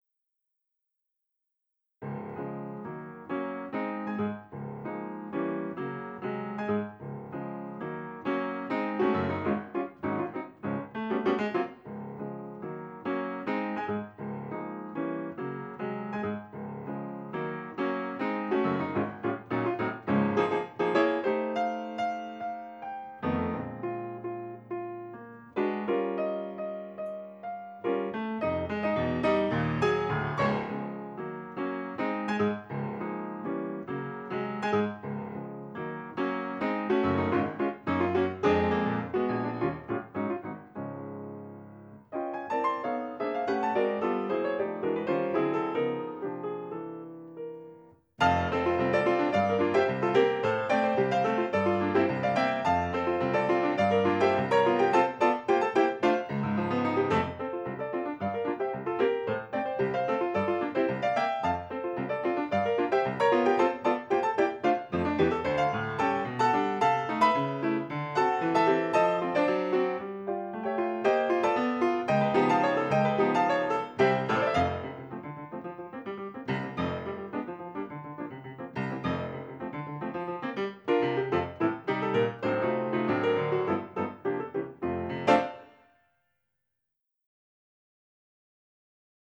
Complete piano music